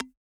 key-confirm.mp3